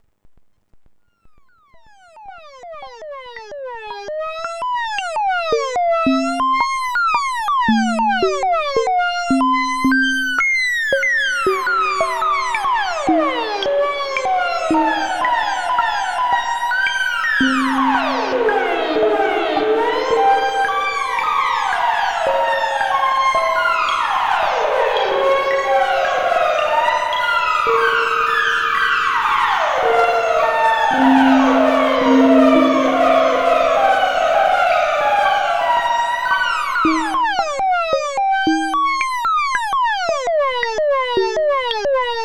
And here is another pair of sound clips comparing the 190 with 2 different sized reverb tanks, using some 200 series modules as well:
Sound Sample with 1AB2A1B "medium" tanks.